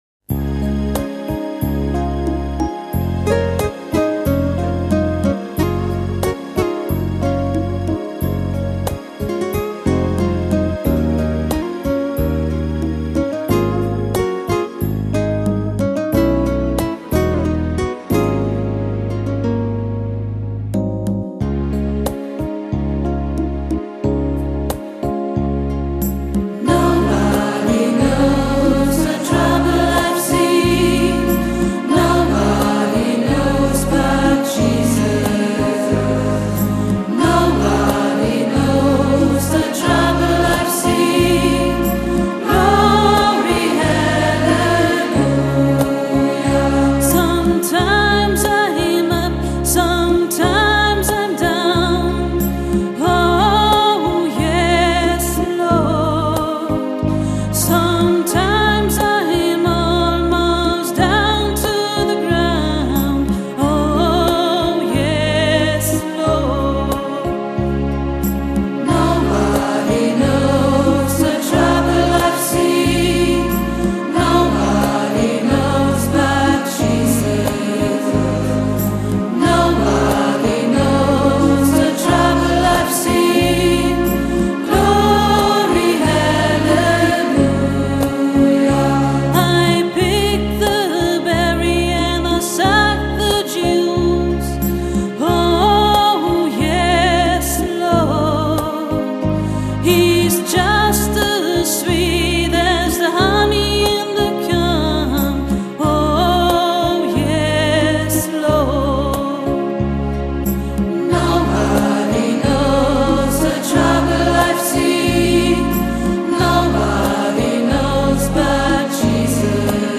Shine Your Light On Me – Gospelchor Klangfarben